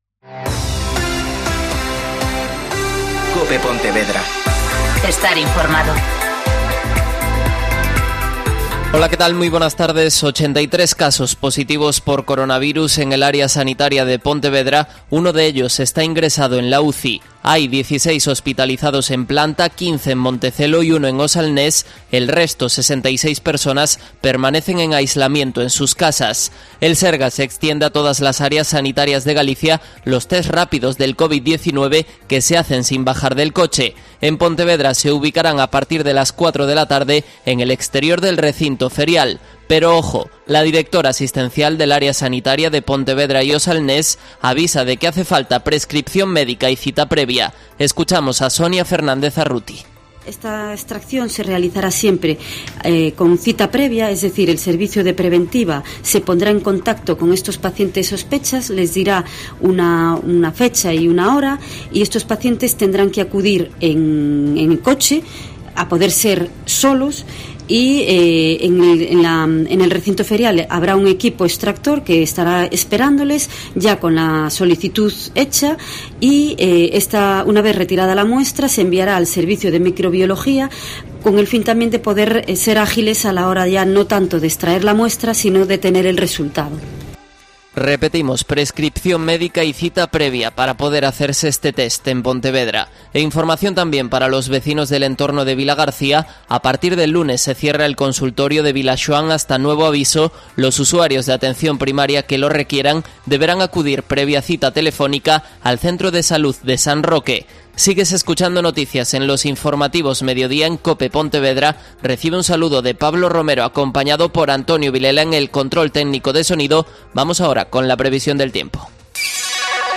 Mediodía COPE Pontevedra (Informativo 14:20)